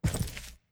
Footstep_Concrete 07.wav